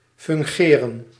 Ääntäminen
Tuntematon aksentti: IPA: /ˈvèrka/